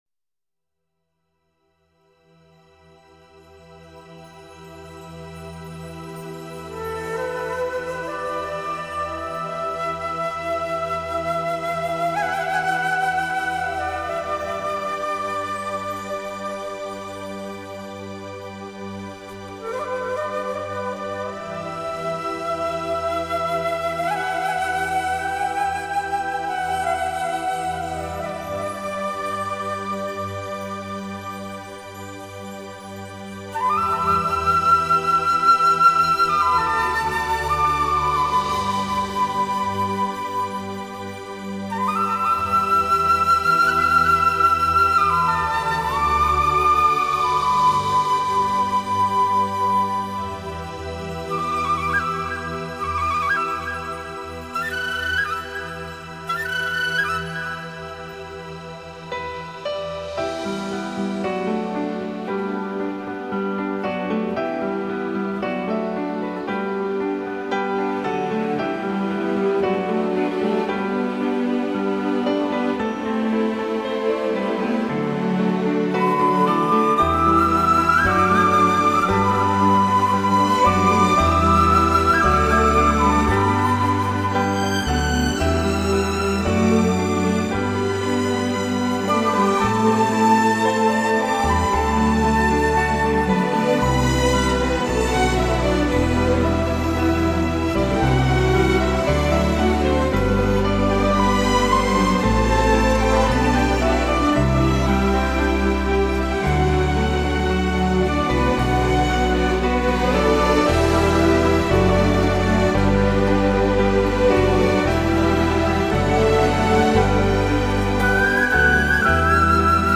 2周前 纯音乐 7